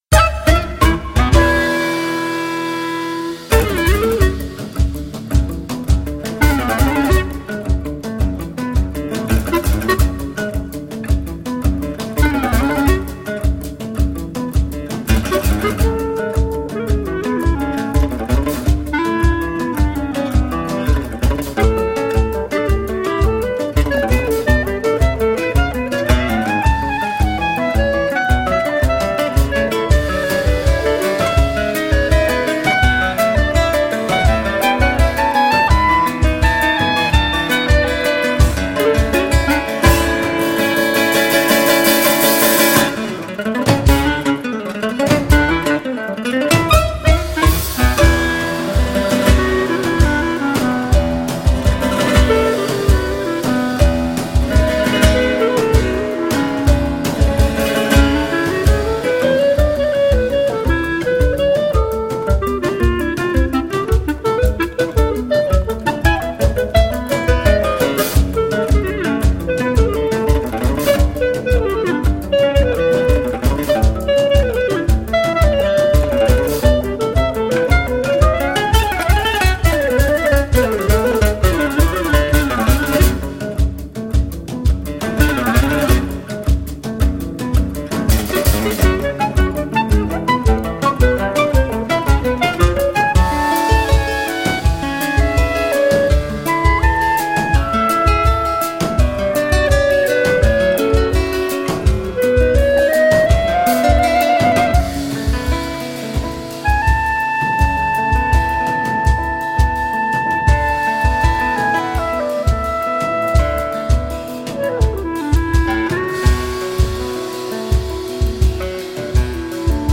Acoustic_Trio_Travesuras.mp3